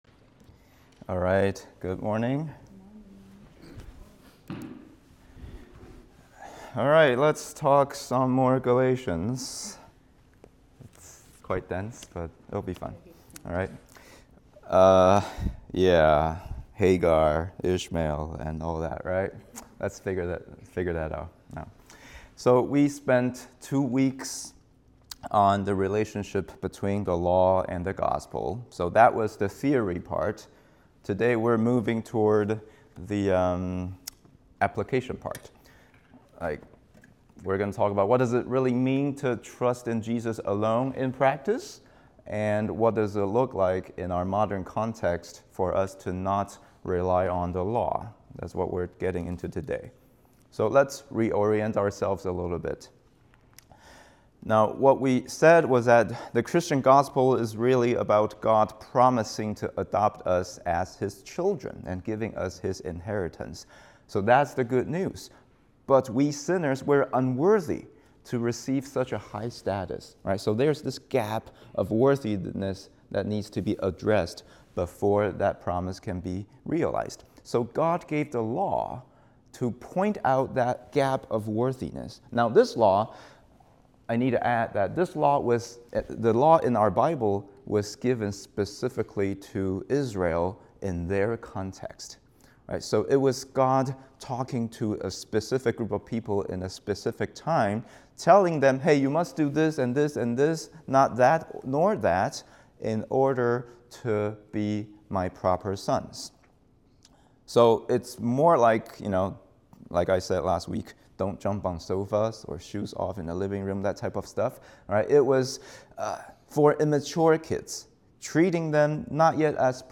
3.29-English-Sermon.m4a